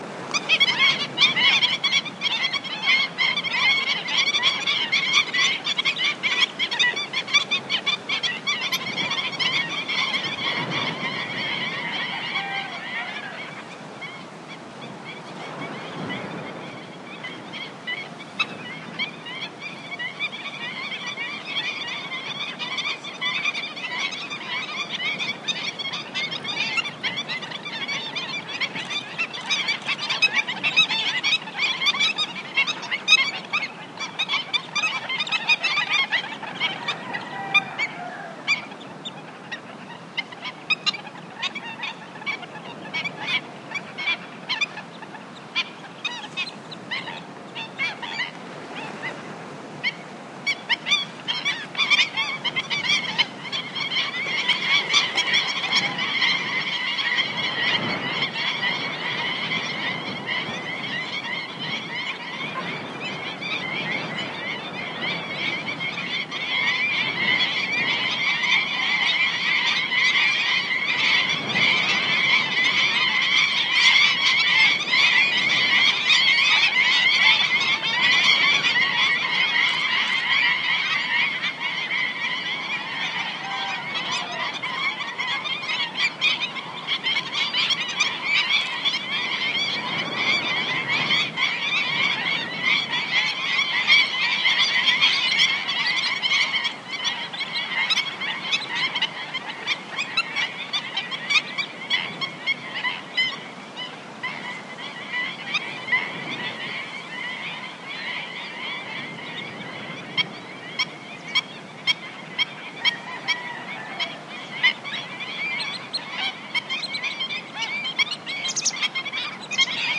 描述：在5米处听到的单只天蓝色翅膀的喜鹊的叫声/'canto' de un rabilargo a unos 5 metros
标签： 道纳拿 现场录音 喜鹊 性质
声道立体声